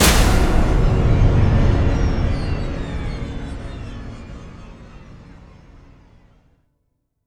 LC IMP SLAM 8.WAV